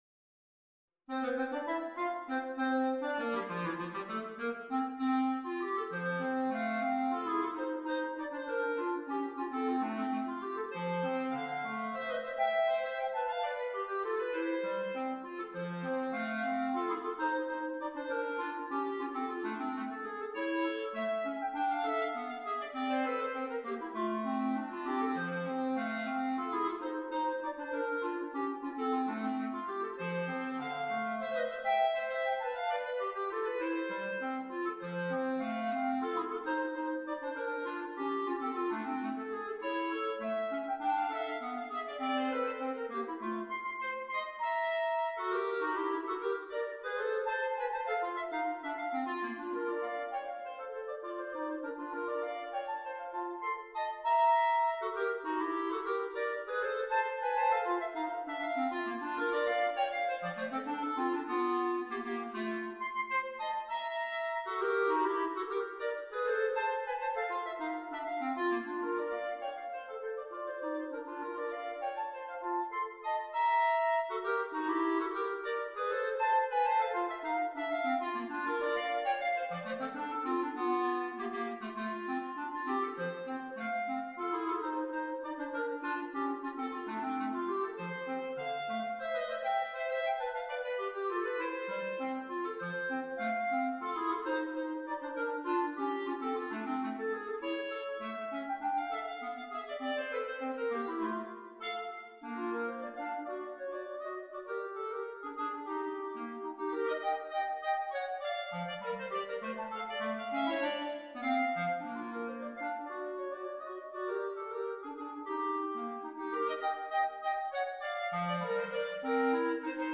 per due clarinetti